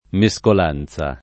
[ me S kol # n Z a ]